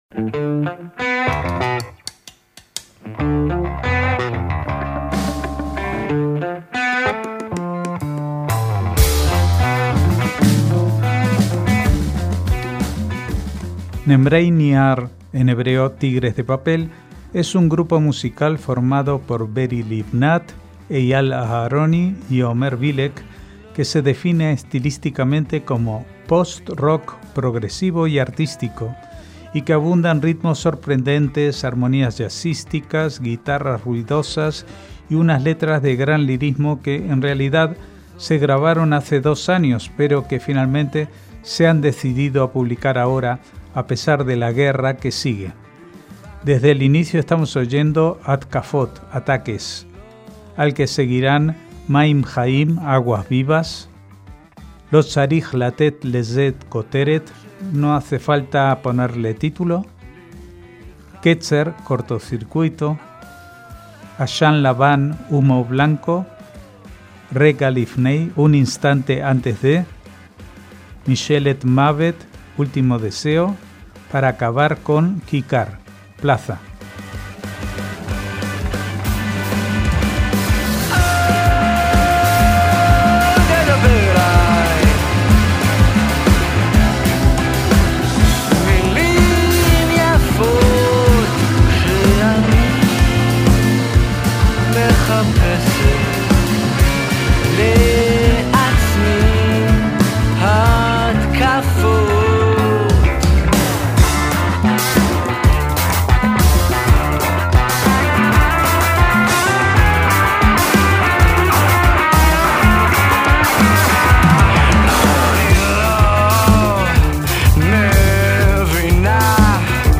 MÚSICA ISRAELÍ
post-rock progresivo y artístico